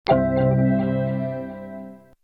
Startljud för din Mac
Mac/Apple-ljud